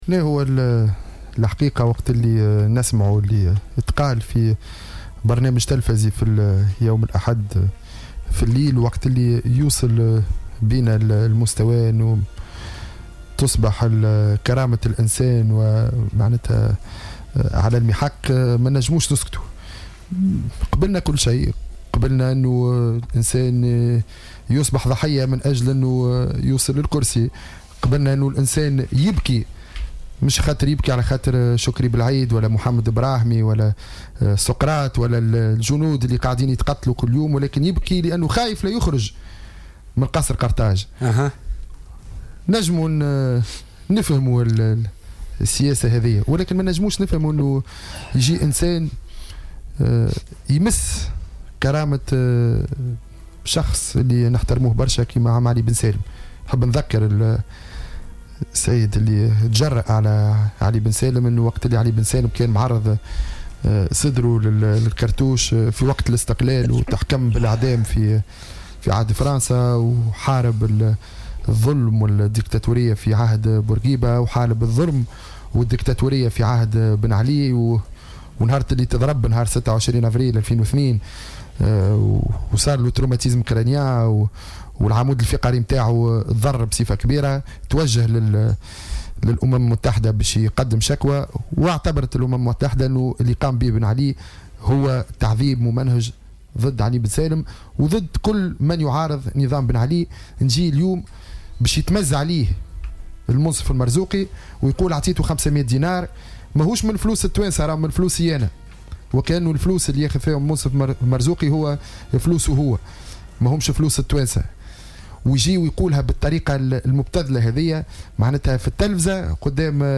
انتقد النائب السابق بالمجلس الوطني التأسيسي،محمود البارودي ضيف برنامج "بوليتيكا" اليوم الثلاثاء تصريحات عدنان منصر في برنامج تلفزي بخصوص الجراية التي قدمها رئيس الجمهورية المنصف المرزوقي لعلي بن سالم.